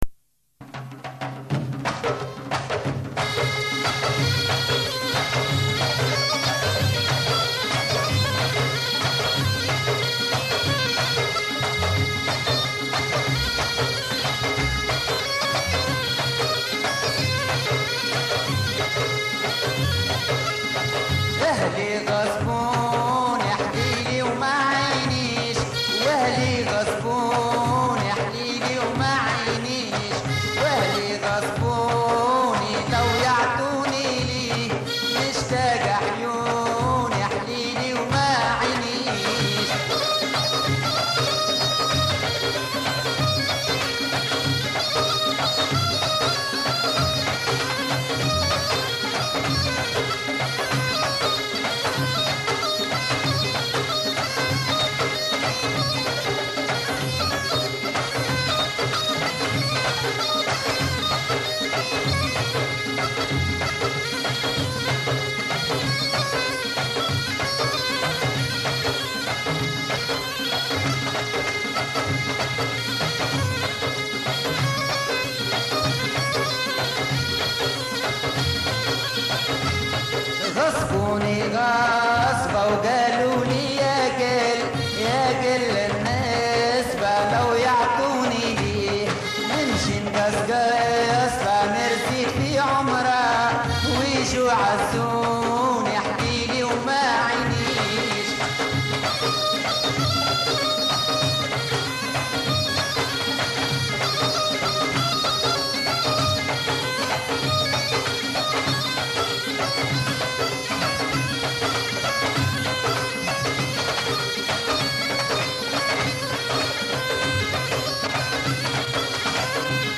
Bienvenue au site des amateurs de Mezoued Tunisien